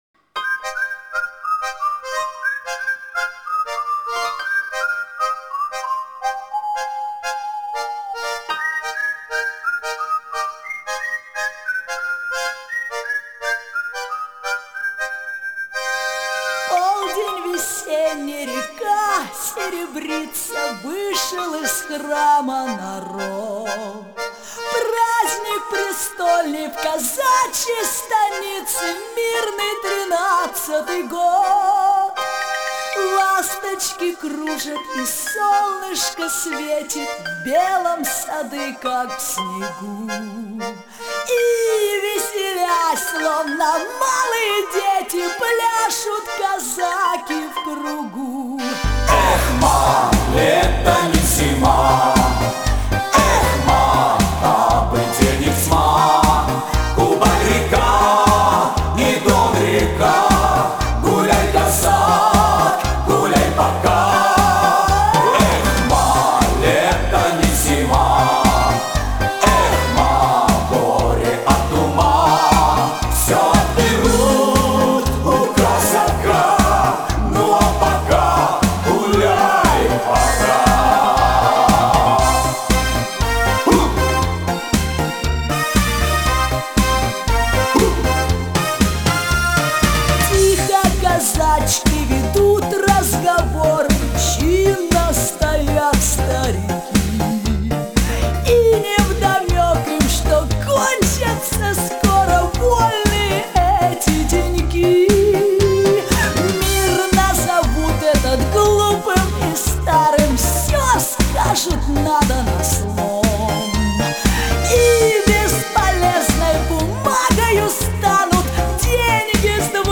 Жанр: Schlager, Pop